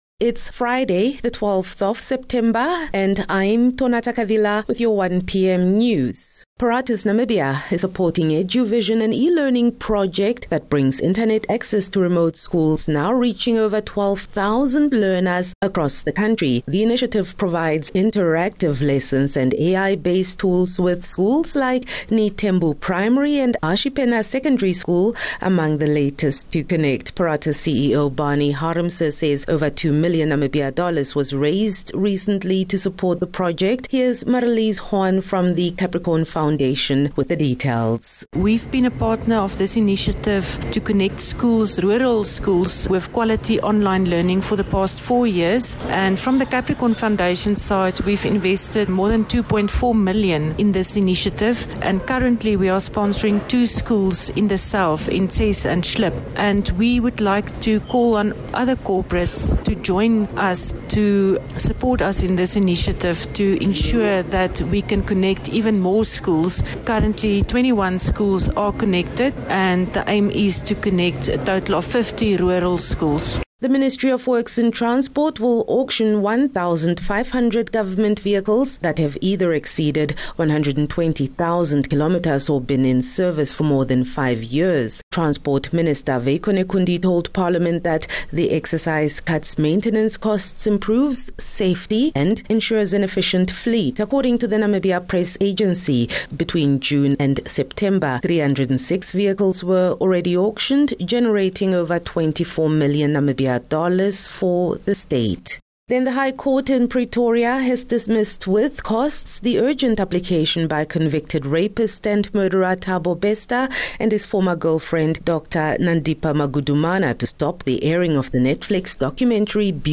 12 Sep 12 September - 1 pm news